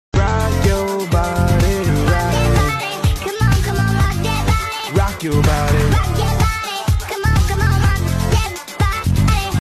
You Just Search Sound Effects And Download. tiktok hahaha sound effect Download Sound Effect Home